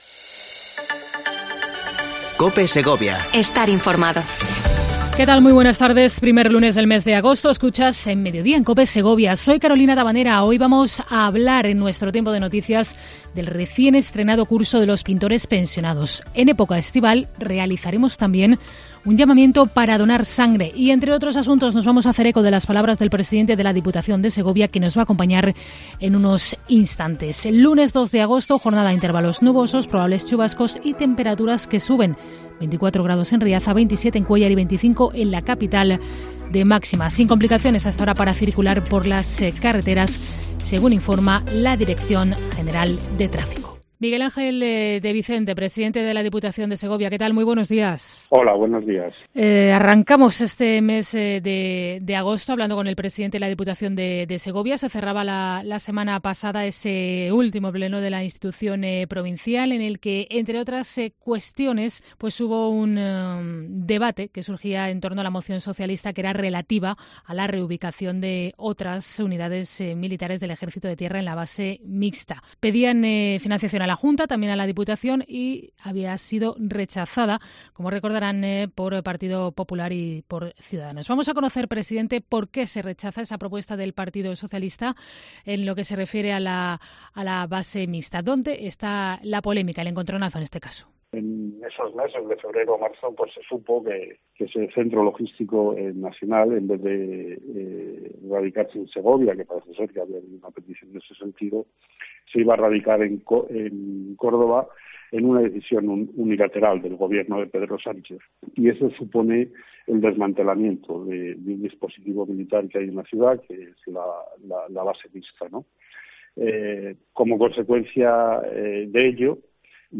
El presidente de la Diputación de Segovia, Miguel Ángel de Vicente, ha hablado en COPE Segovia sobre la polémica base militar mixta, los Fondos Europeos y un balance de su gobierno